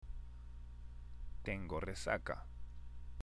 もちろん巻き舌の「レ」ですね。